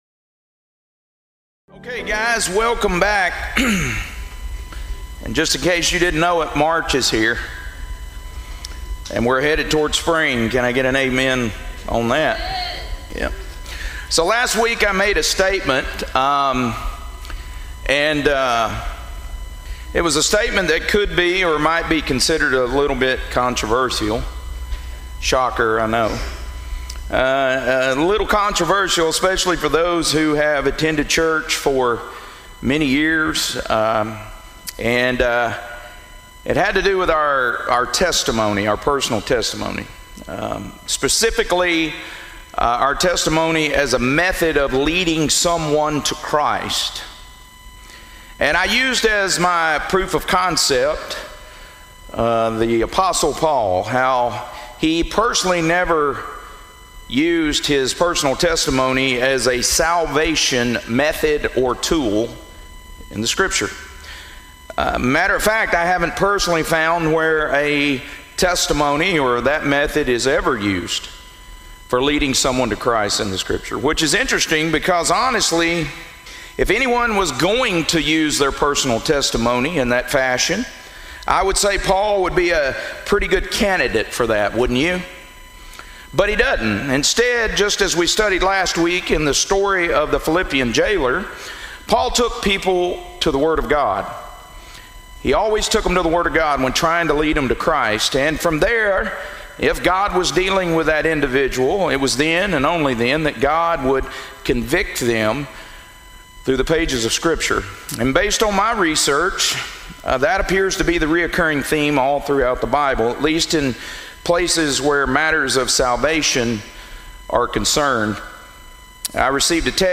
2 Corinthians - Lesson 12C | Verse By Verse Ministry International